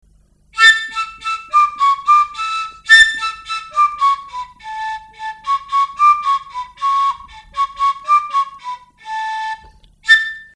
S píšťalkami veľmi - majú pekný prenikavý zvuk.
Zvuk píšťalky
pistalka.mp3